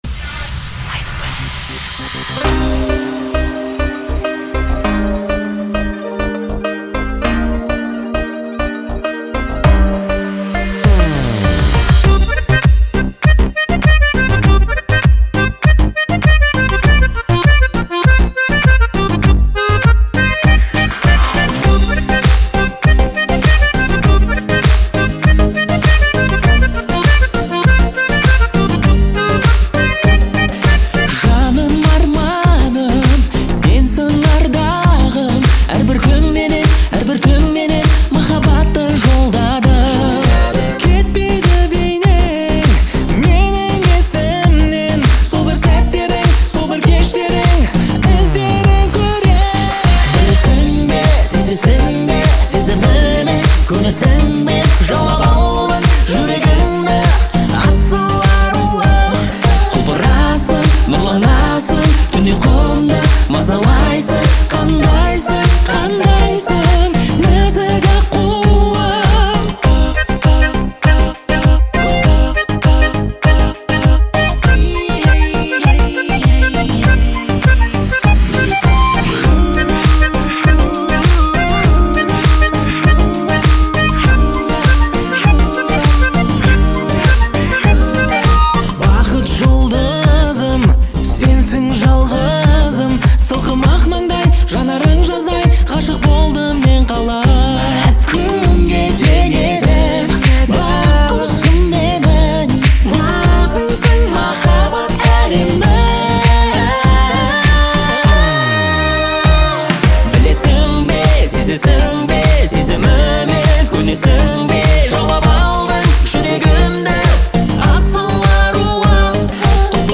это яркий пример казахского поп-рока
гармоничными гитарными рифами и запоминающимися мелодиями